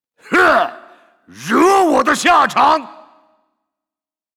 击杀语音